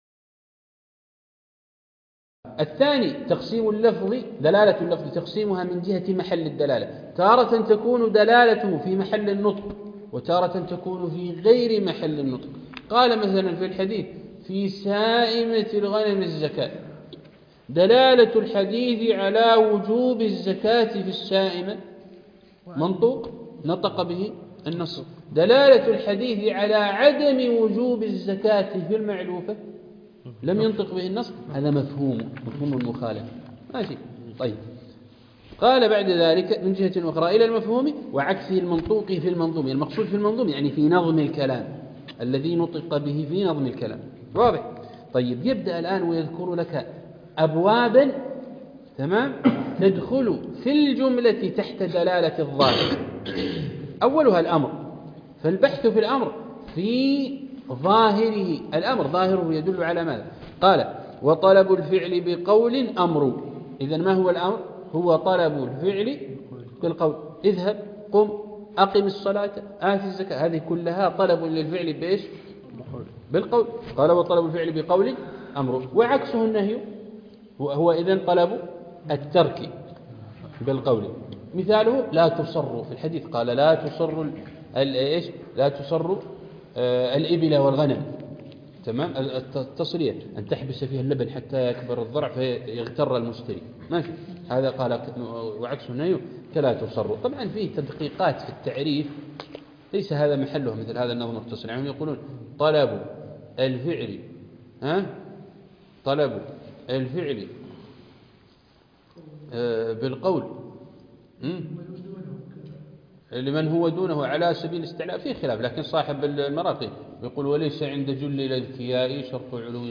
عنوان المادة الدرس (20) شرح النظم المعسول في تعليم الأصول